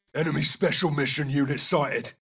voices/eng/adult/male/Ghost_MW2_24khz.wav
Ghost_MW2_24khz.wav